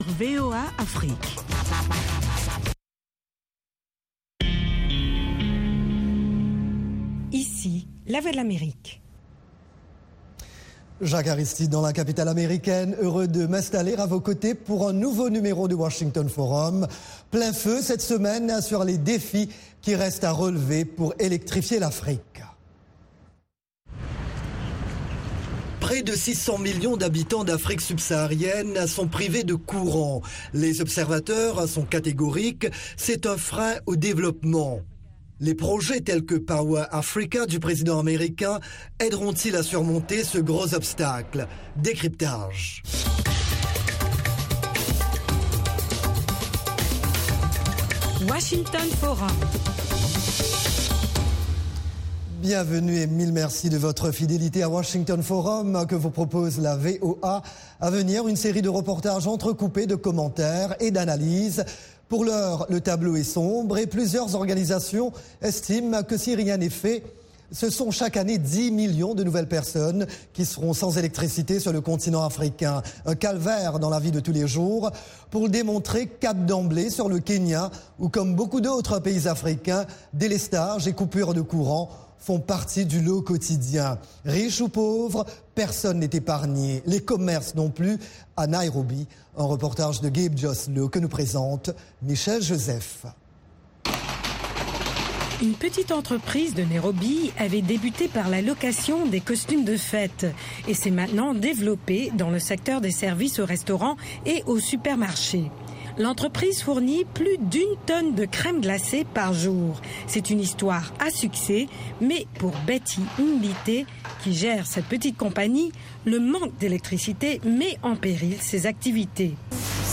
Washington Forum : 30 minutes d'actualité africaine, américaine et internationale. Economie, politique, santé, religion, sports, science, multimédias: nos experts répondent à vos questions en direct, via des Live Remote, Skype, et par téléphone de Dakar à Johannesburg, en passant par le Caire, New York, Paris et Londres. Cette émission est diffusée en direct par satellite à l’intention des stations de télévision et radio partenaires de la VOA en Afrique francophone.